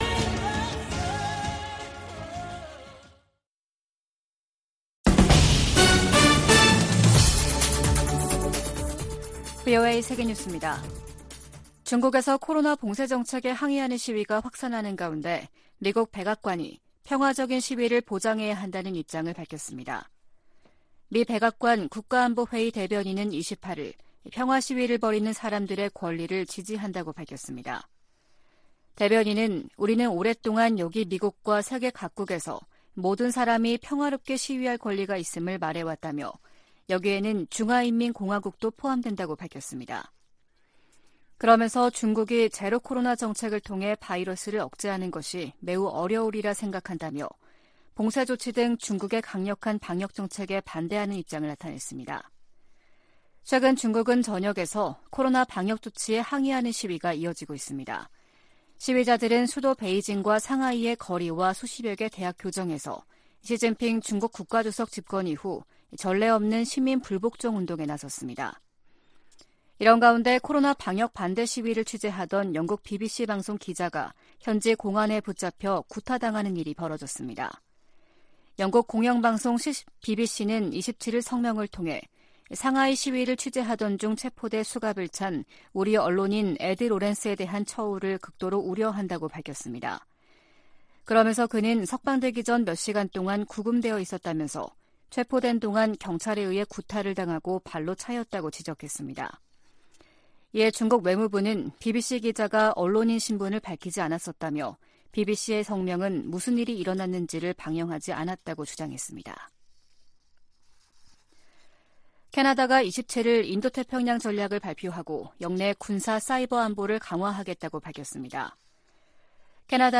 VOA 한국어 아침 뉴스 프로그램 '워싱턴 뉴스 광장' 2022년 11월 29일 방송입니다. 김정은 북한 국무위원장은 최근의 대륙간탄도미사일 시험발사를 현지 지도하며 대륙간 탄도미사일 부대를 처음 언급했습니다. 북한의 장거리 탄도미사일 발사가 미국 본토에 대한 위협이 되지 않으나 북한이 역내에 제기하는 위협을 우려한다고 백악관 고위 관리가 밝혔습니다.